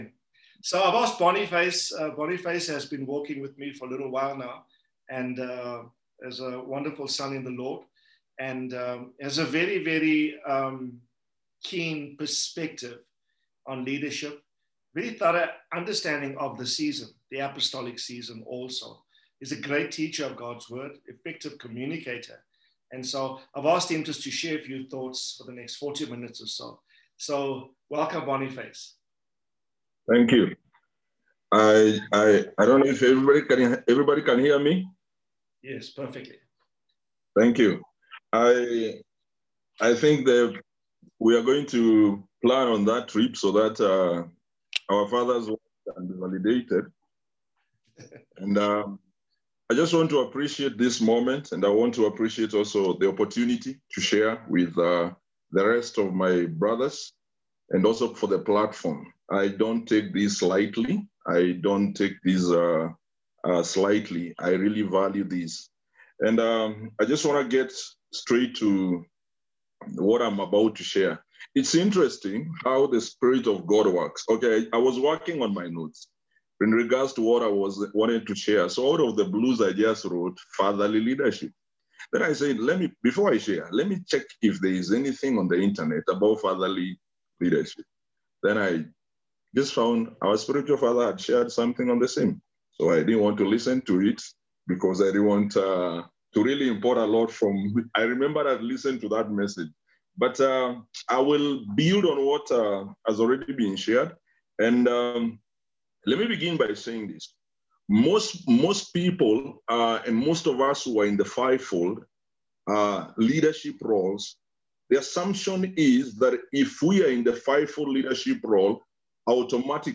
Audio Teachings
Zoom Meeting - Kenya, Trinidad, Barbados, Cayman Islands, Zimbabwe, Canada